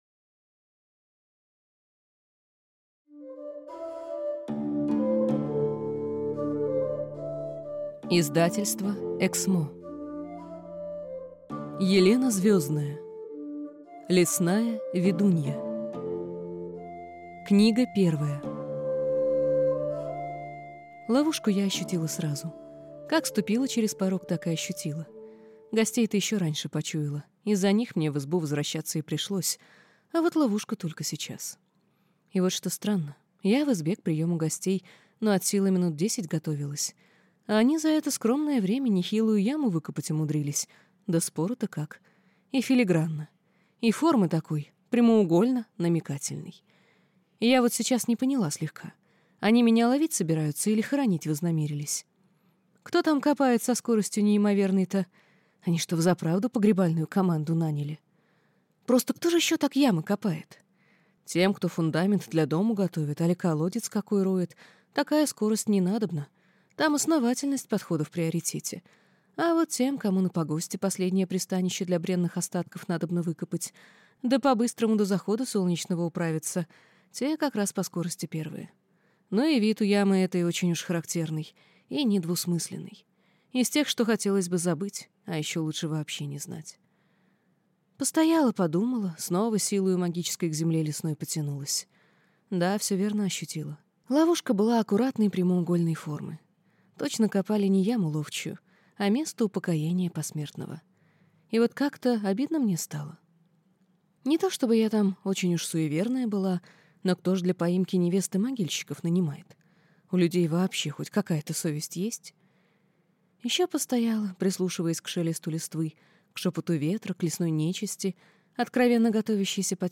Аудиокнига Лесная ведунья. Книга первая | Библиотека аудиокниг